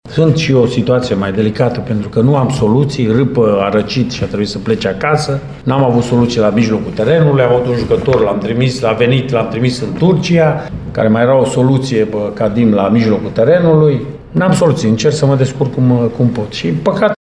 Rednic s-a referit și la lipsa de soluții din anumitele compartimente pentru meciul de azi: